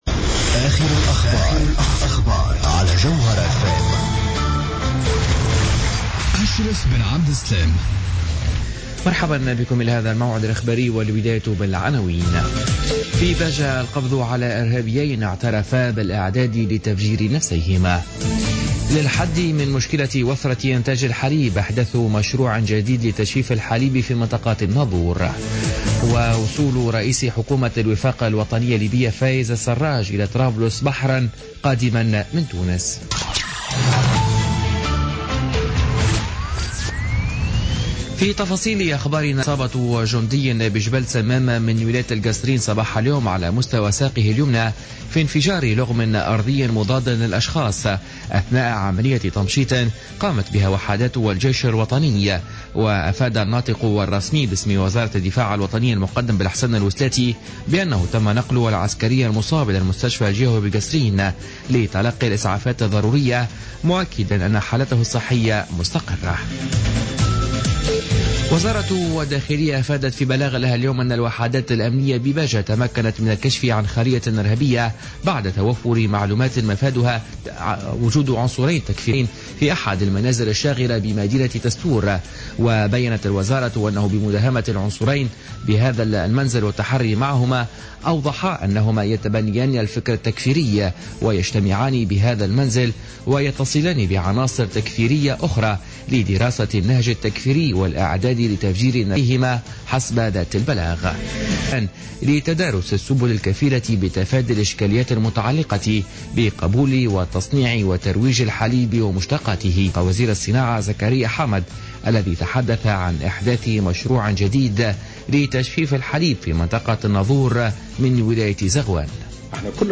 نشرة أخبار السابعة مساء ليوم الأربعاء 30 مارس 2016